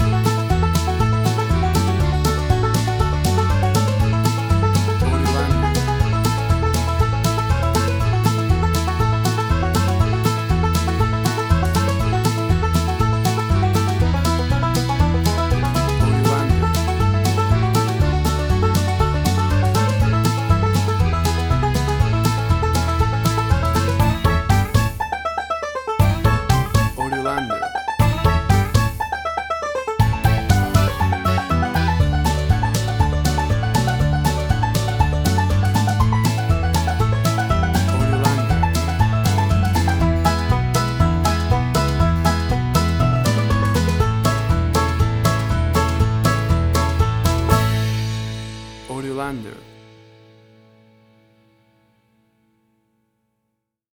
WAV Sample Rate: 16-Bit stereo, 44.1 kHz
Tempo (BPM): 120